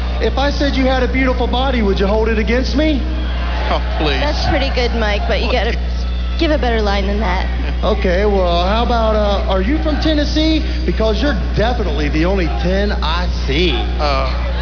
To help move the gimmick along, WCW gave Awesome his own talk show segment, the Lava Lamp Lounge.
horrible pick up lines for his female guests.